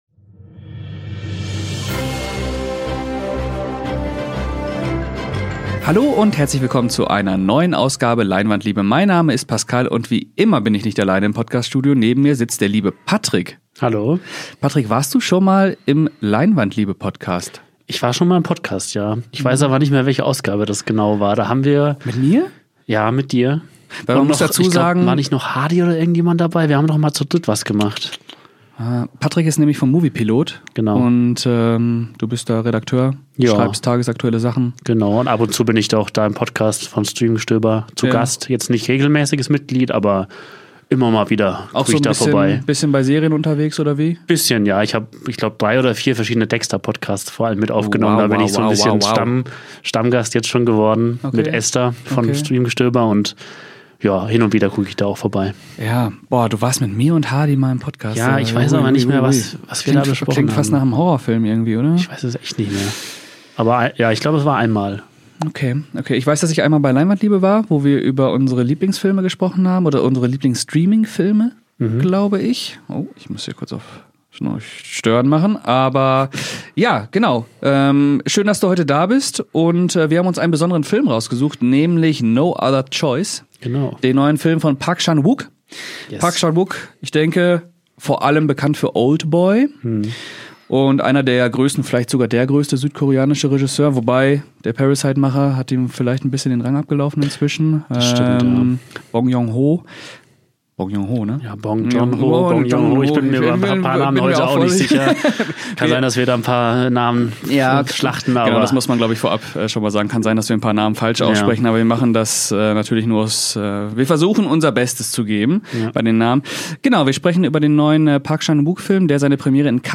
ins Podcast-Studio eingeladen, um genau diese Frage zu diskutieren